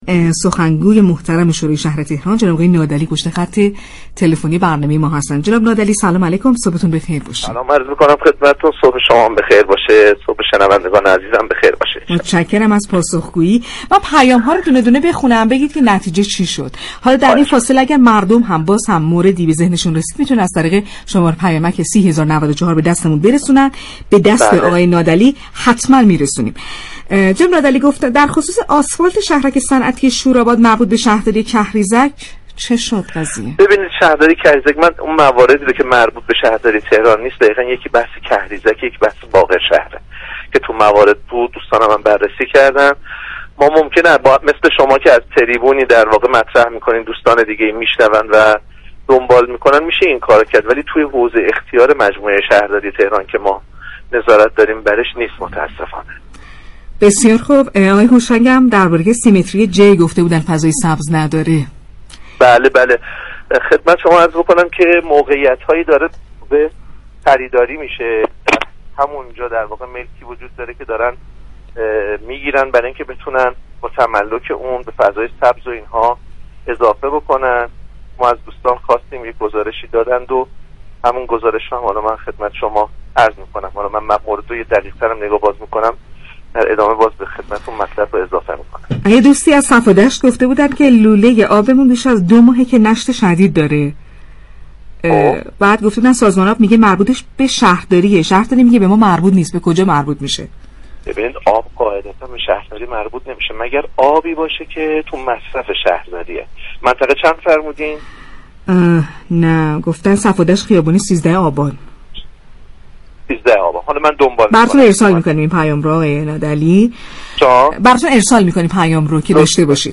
به گزارش پایگاه اطلاع رسانی رادیو تهران، علیرضا نادعلی سخنگوی شورای اسلامی شهر تهران در گفت و گو با «شهر آفتاب» درخصوص بهبود فضای سبز محدوده 30 متری جی واقع در منطقه 10 شهرداری تهران، اظهار داشت: طی توافقی كه با بنیاد مستضعفان برای تملك ملكی نبش خیابان جرجانی صورت گرفته است؛ حدود 11 هزار متر مربع فضای سبز در محدوده 30 متری جی ایجاد می‌شود.